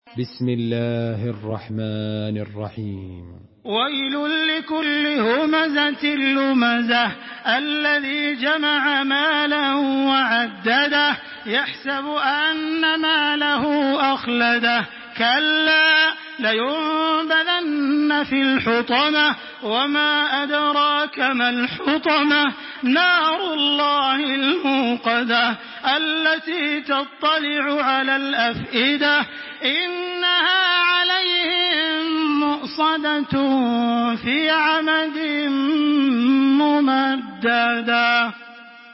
تحميل سورة الهمزة بصوت تراويح الحرم المكي 1426
مرتل